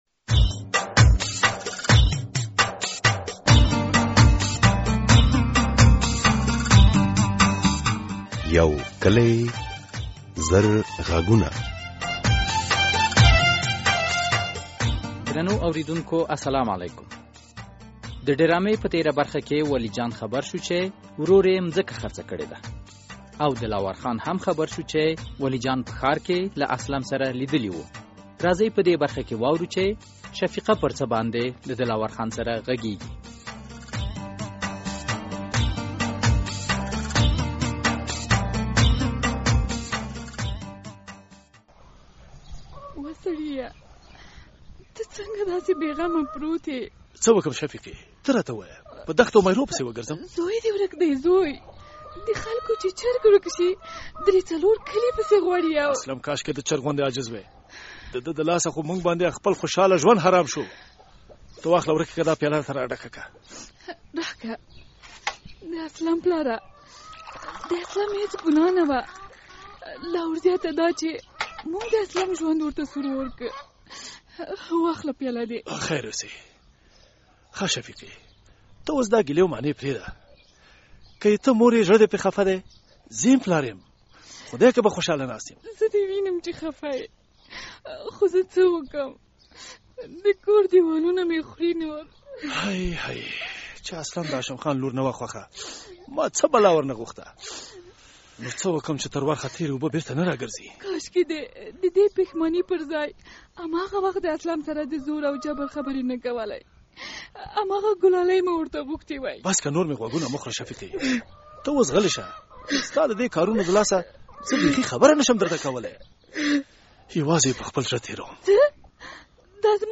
یو کلي او زرغږونه ډرامه هره اوونۍ د دوشنبې په ورځ څلور نیمې بجې له ازادي راډیو خپریږي.